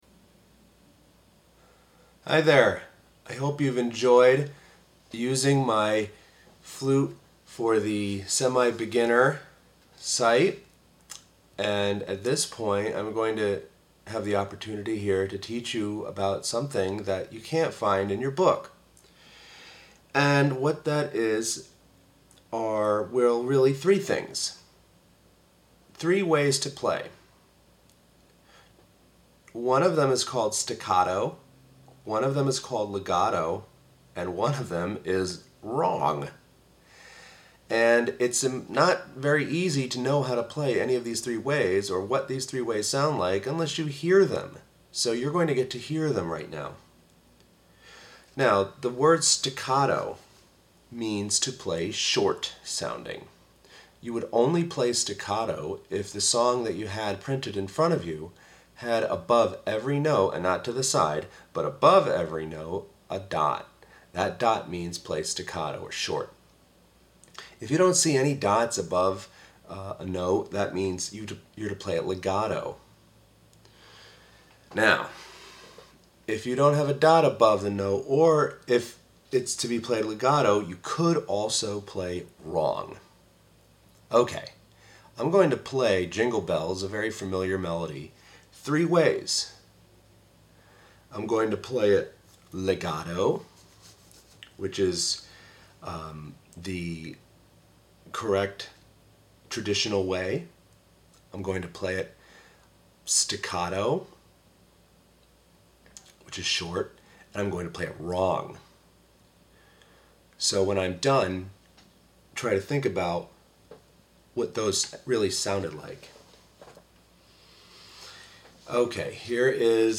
fluteaudioblog.mp3